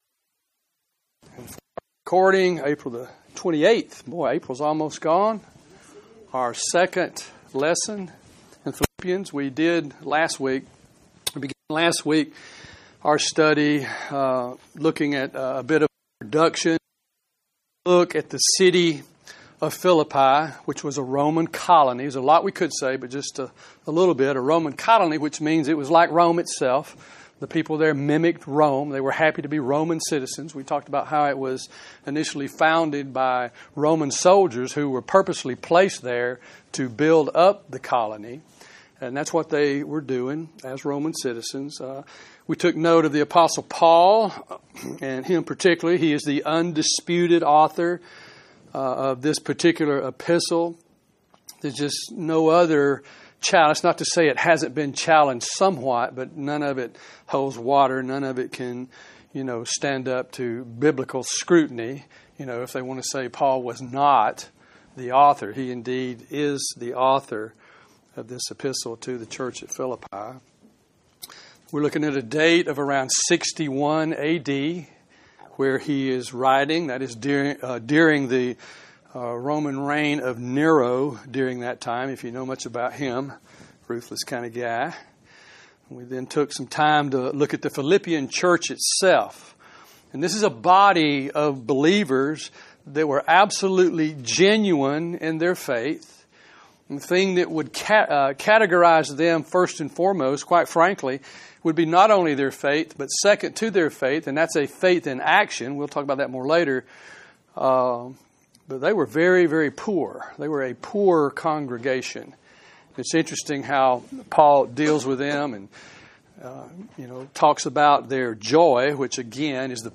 Series: Bible Studies, Philippians